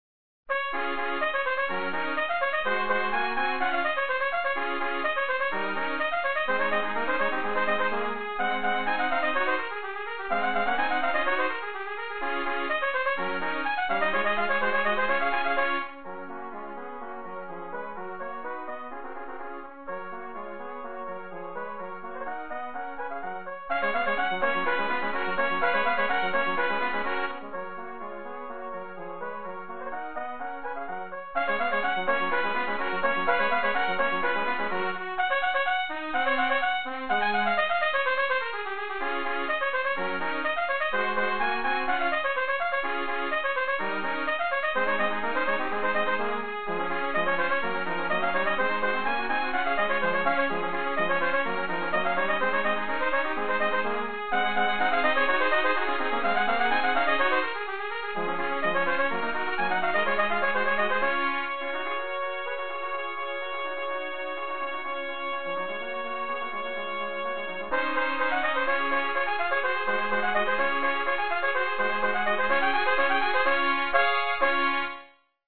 Trio